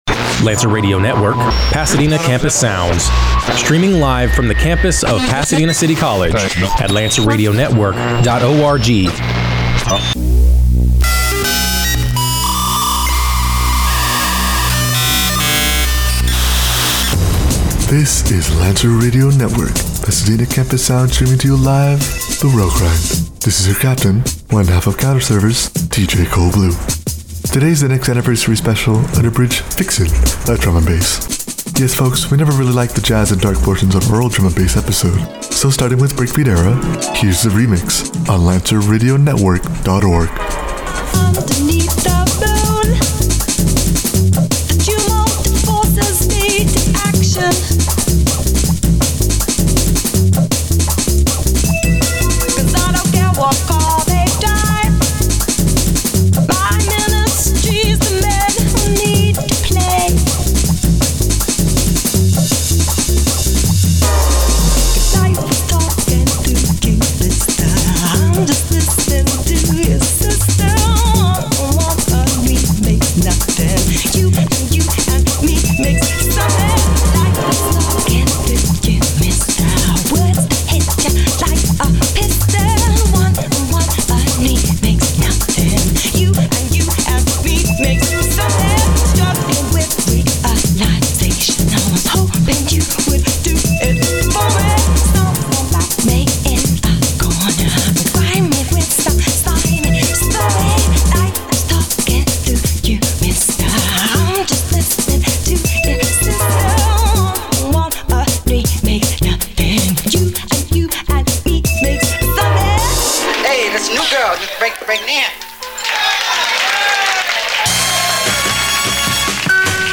S3: Ep2| |Anniversary I: An Abridged Fixin’ of Drum & Bass